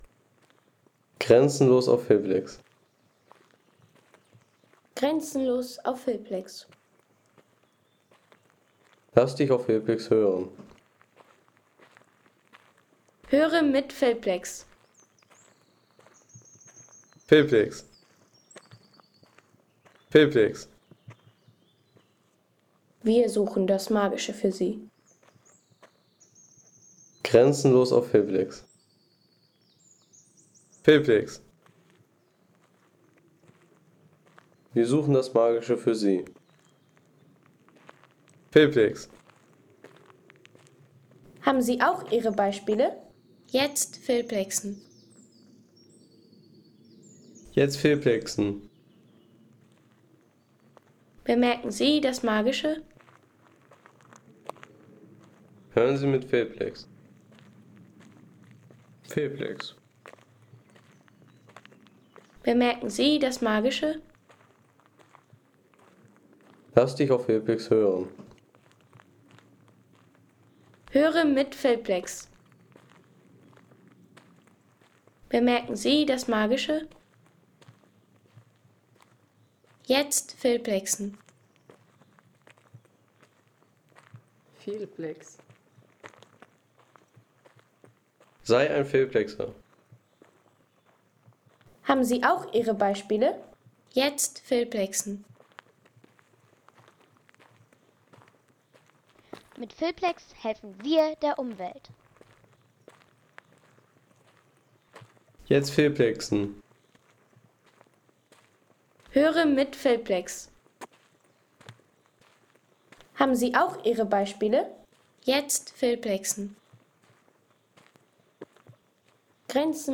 Wandern im Wald - Sächsische Schweiz
Ein ruhiger Schritt bei einer Stiegentour – Sächsische Schweiz.